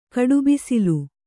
♪ kaḍubisilu